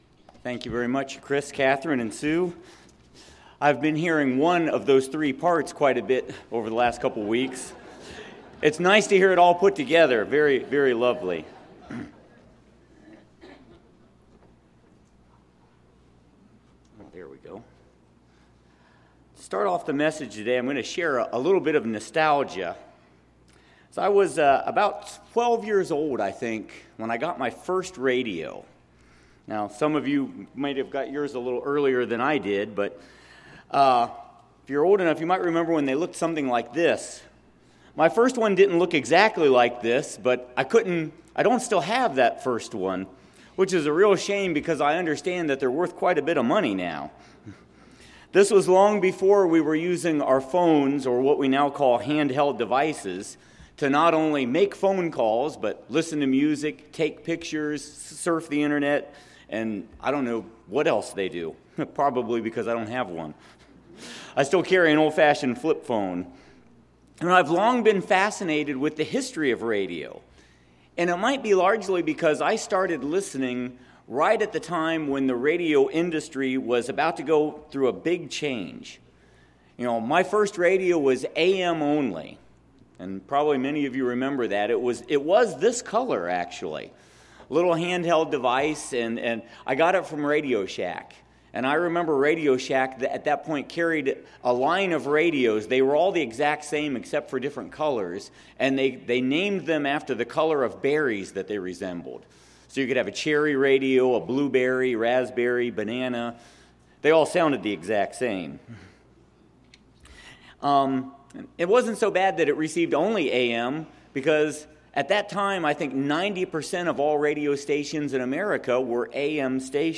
This sermon was given at the Cincinnati, Ohio 2016 Feast site.